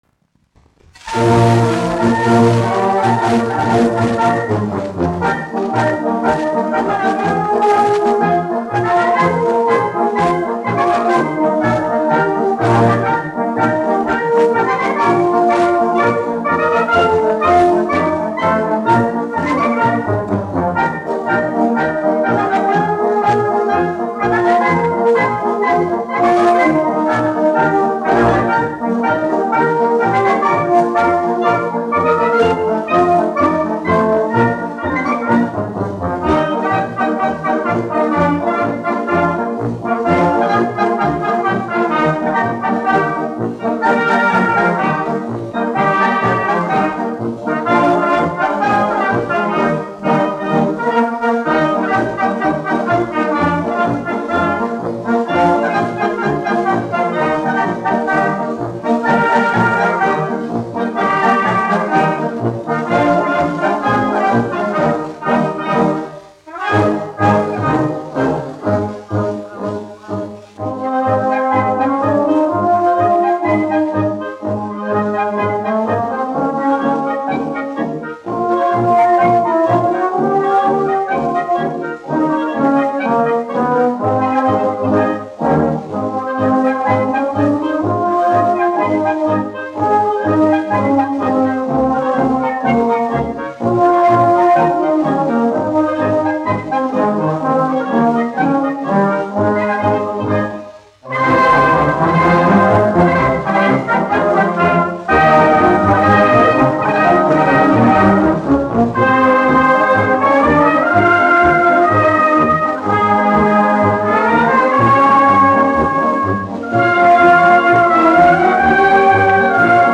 Latvijas 4. Valmieras kājnieku pulka orķestris, izpildītājs
1 skpl. : analogs, 78 apgr/min, mono ; 25 cm
Marši
Pūtēju orķestra mūzika
Skaņuplate